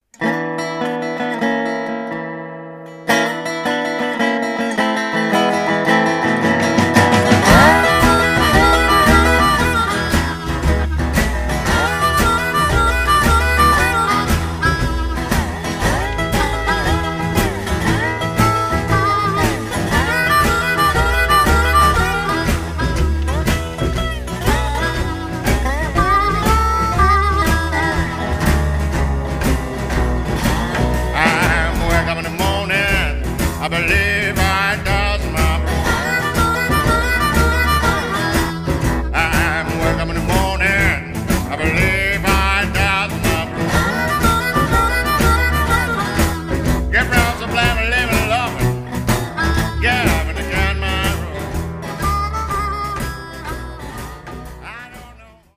lead vocal, acoustic guitar, dobro, slide
acoustic guitar, 12-string-guitar, vocal
harmonica
drums
double bass